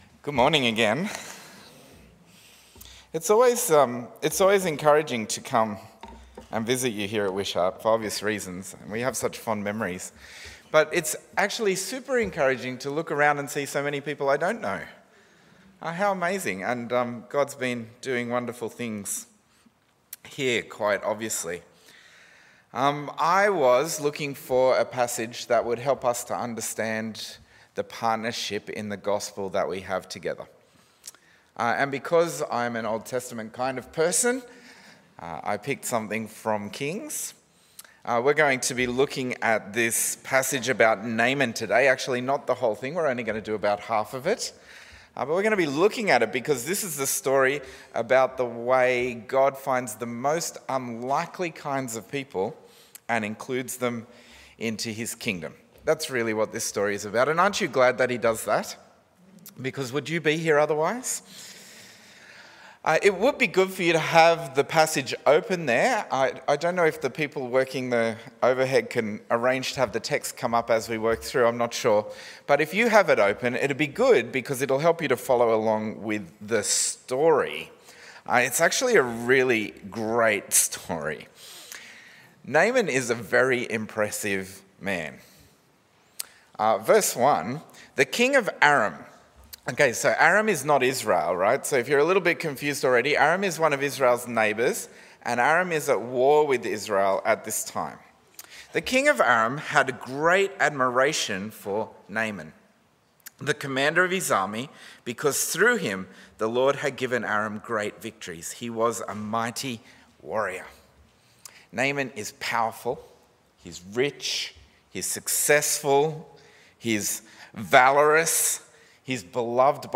Sermon on 2 Kings 5:1-14